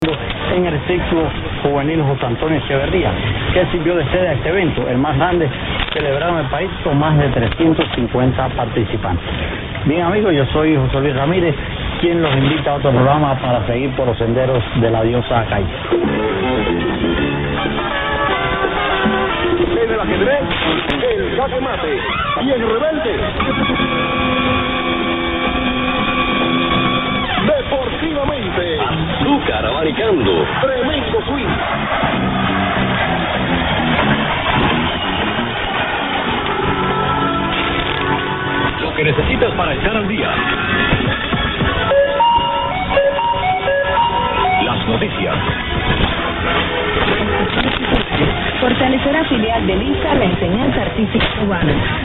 100826_0502_1580_unid_yl_15-80_am.mp3